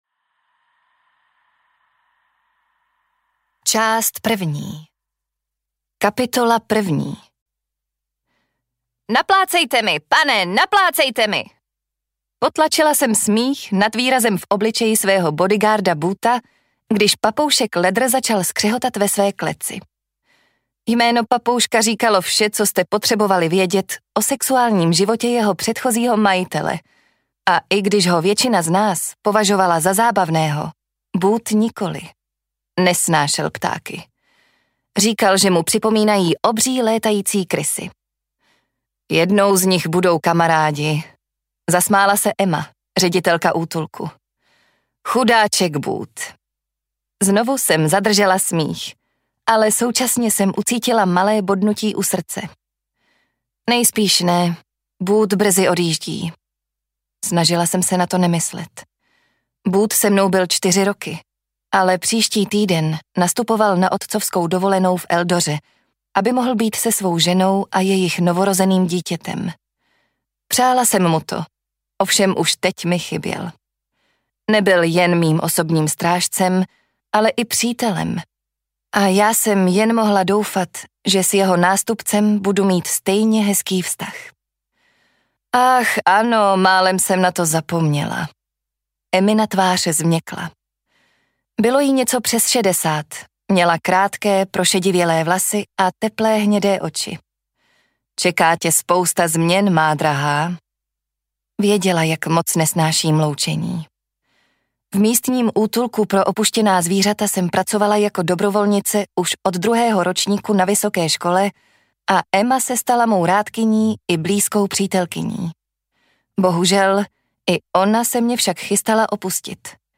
Twisted Games: Hry na ostří nože audiokniha
Ukázka z knihy